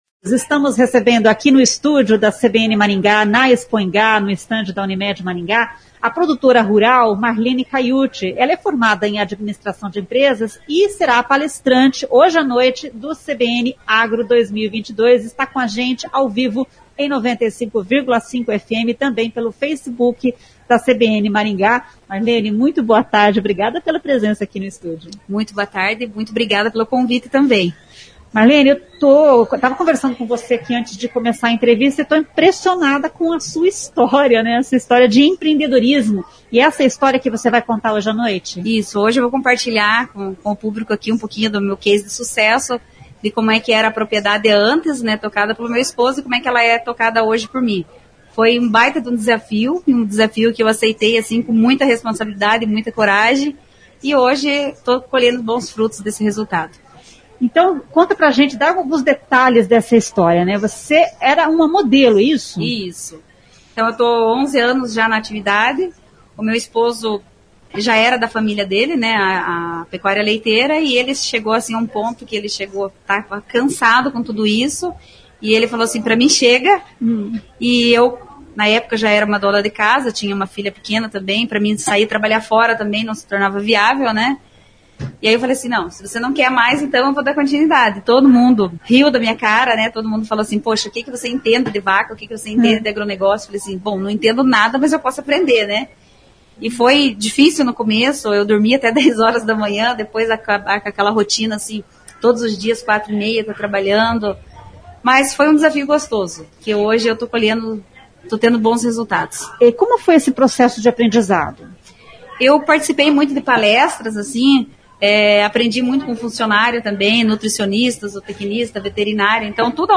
Produtora rural fala sobre empreendedorismo feminino no CBN Agro